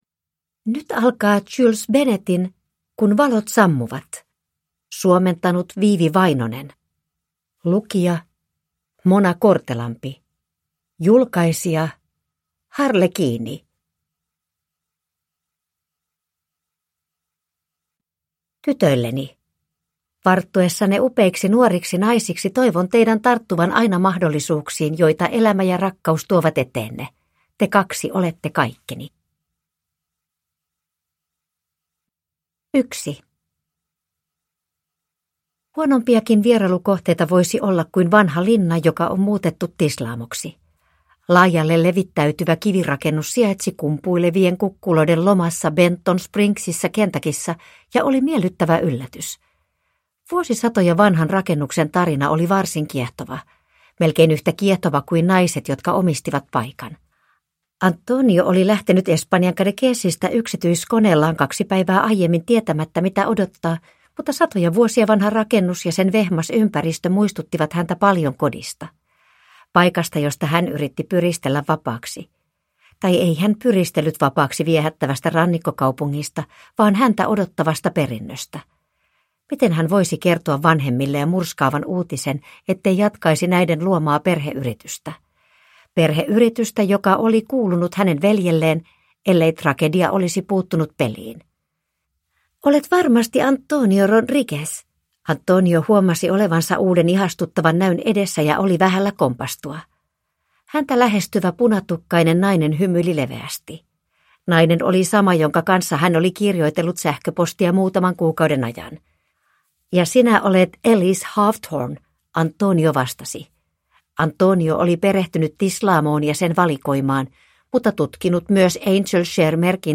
Kun valot sammuvat – Ljudbok – Laddas ner